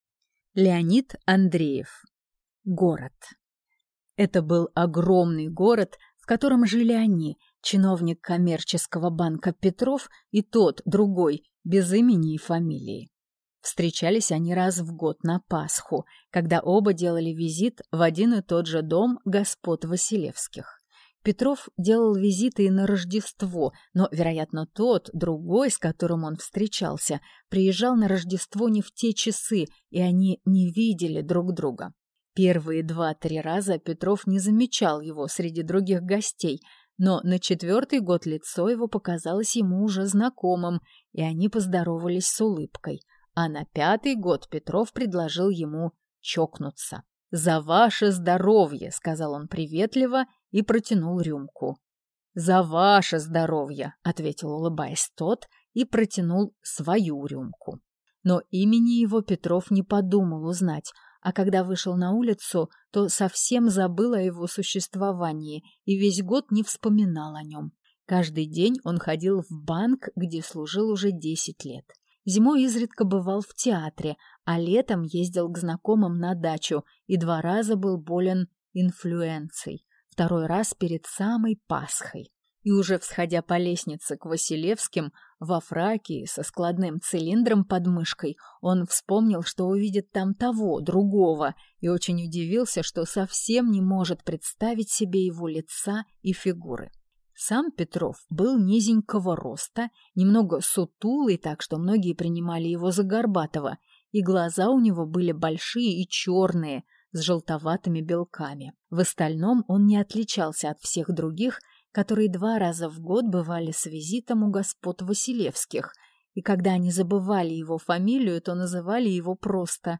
Aудиокнига Город
Читает аудиокнигу